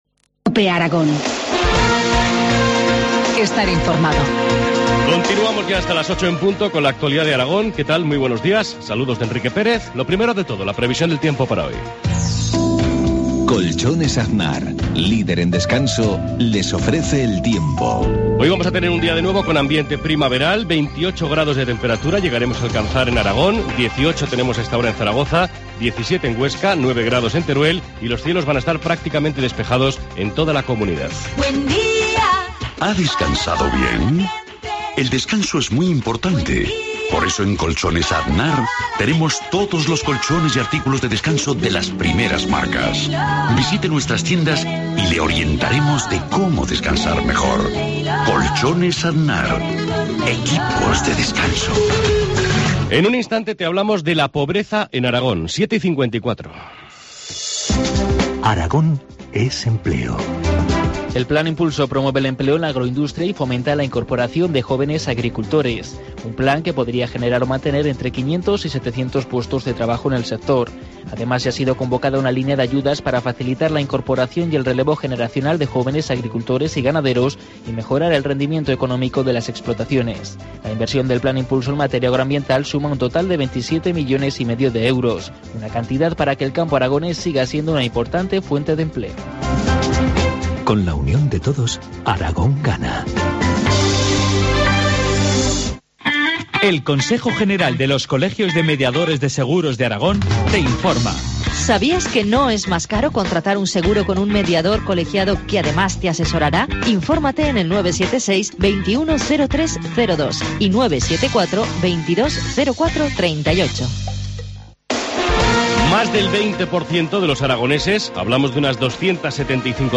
Informativo matinal, viernes 18 de octubre, 7.25 horas